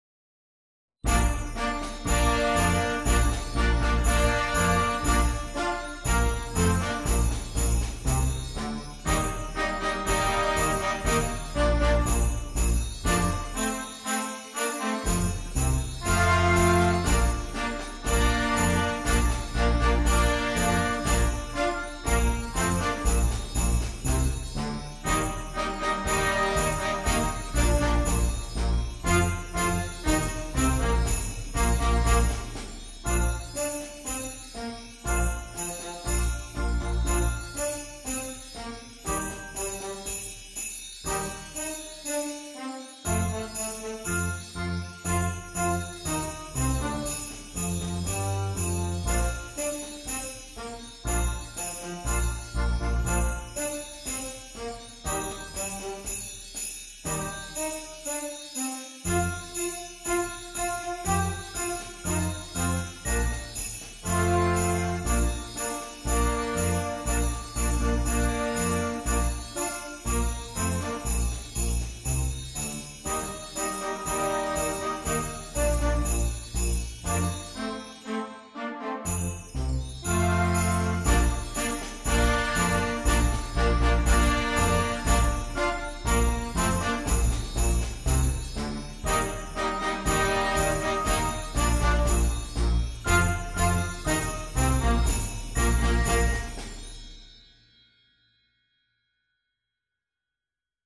light version